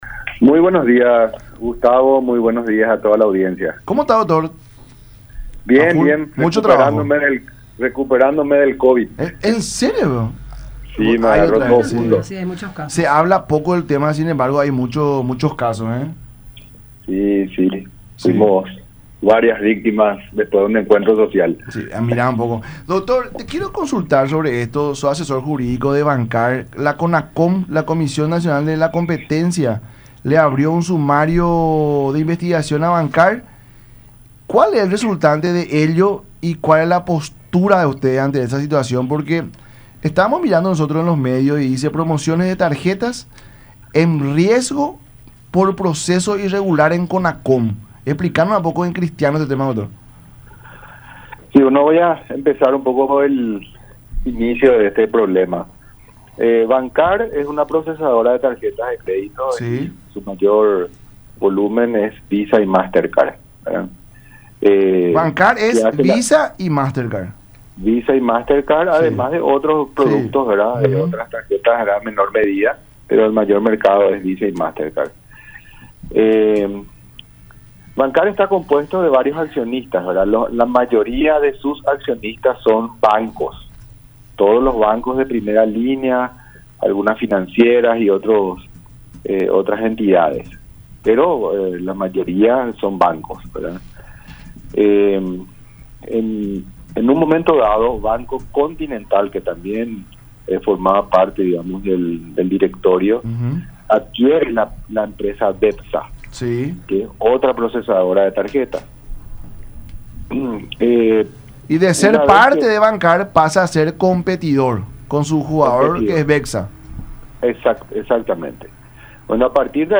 “Si es justo o no , decide un órgano independiente que debería ser la Conacom, hay un director de investigación. Continental dice que con la decisión de Bancar, no tendría la libertad de  contratar con otra empresa, decide no firmar la clausula de exclusividad, pero denuncian el hecho no tener la libertad de elegir con quien trabajar”, dijo en el programa “La Mañana De Unión” por radio La Unión Y Unión Tv.